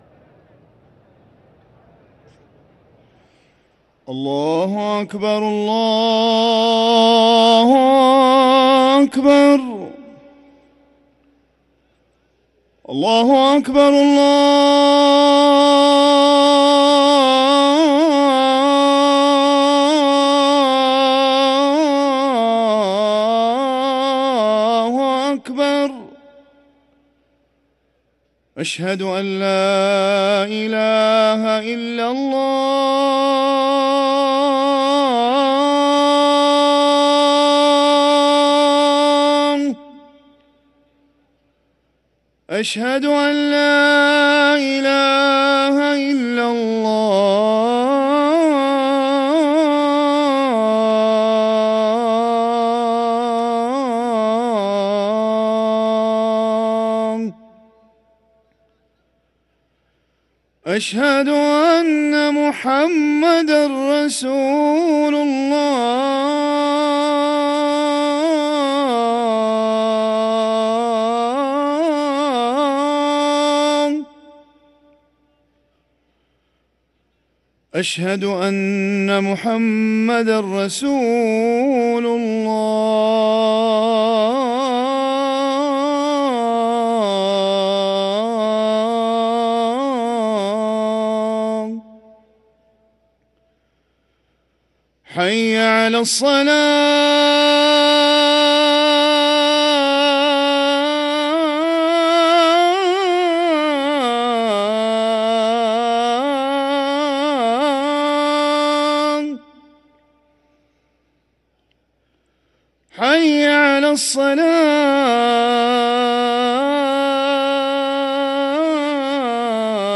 أذان الجمعة الأول
ركن الأذان